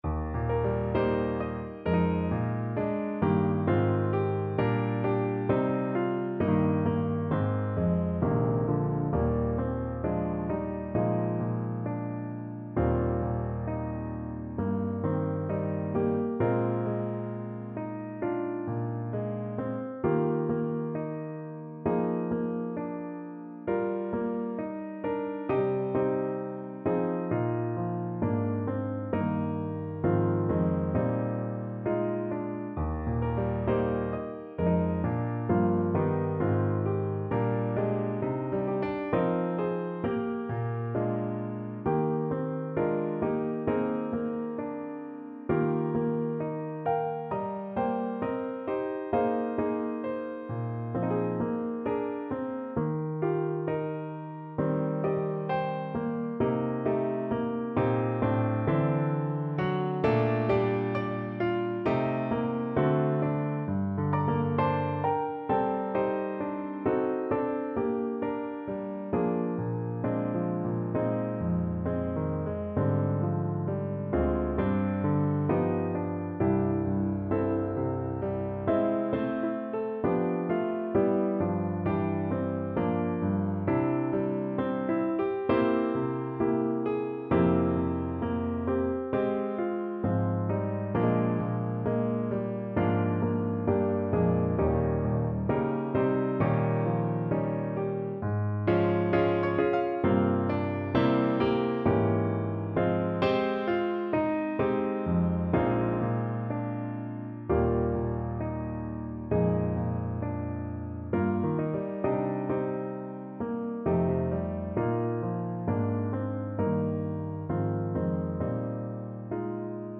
4/4 (View more 4/4 Music)
Classical (View more Classical Voice Music)